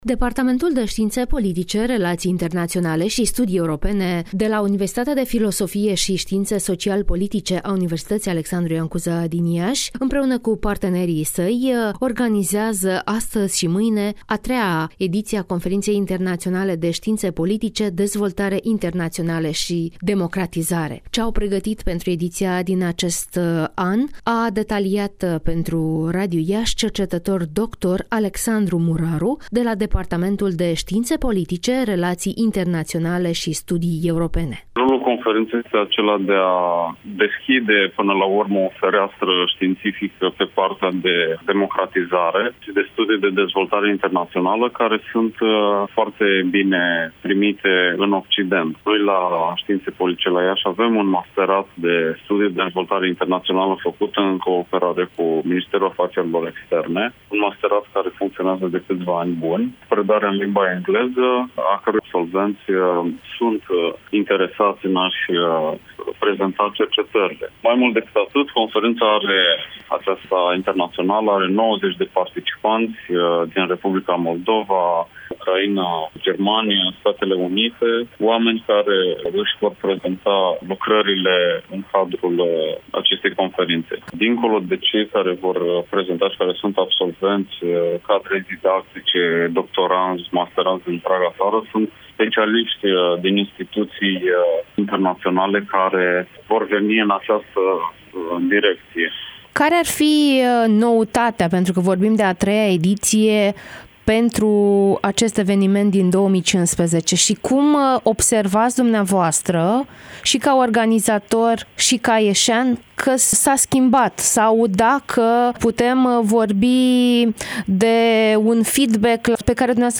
(INTERVIU) Astăzi începe conferința internațională de științe politice